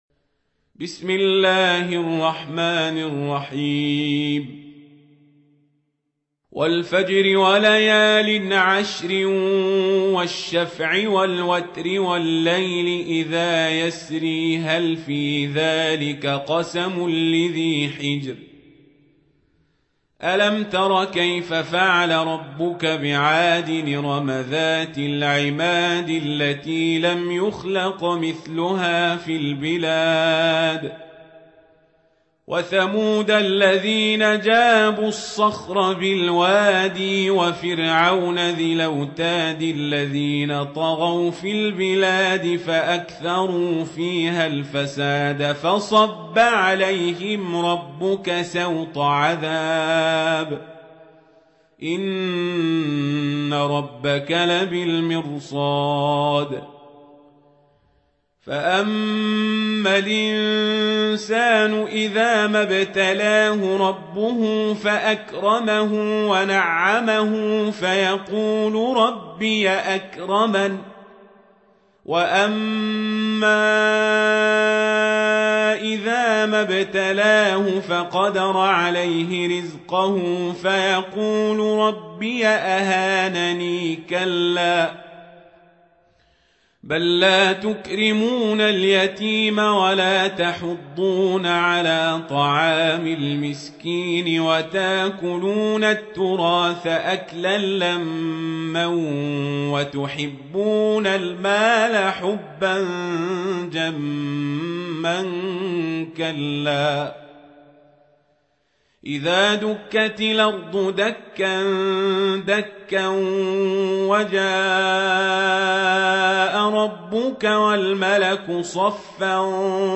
سورة الفجر | القارئ عمر القزابري